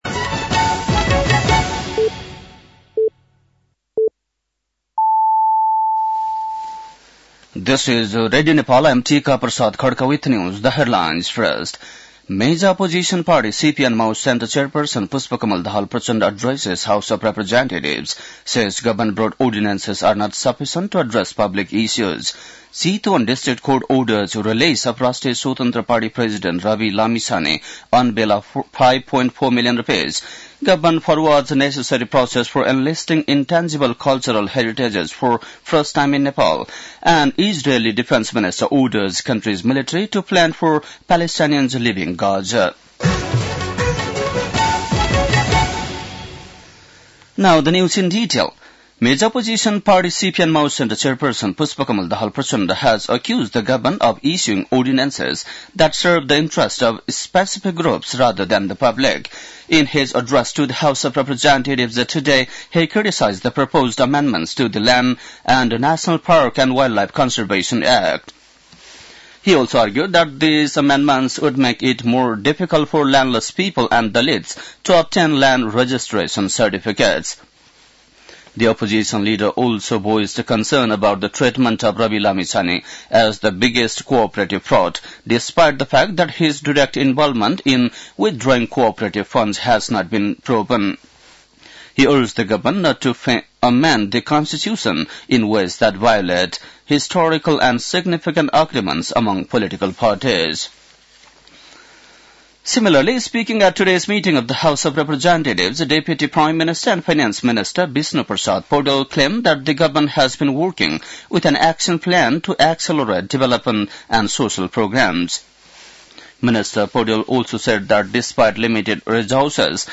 बेलुकी ८ बजेको अङ्ग्रेजी समाचार : २५ माघ , २०८१
8-pm-english-news-10-24.mp3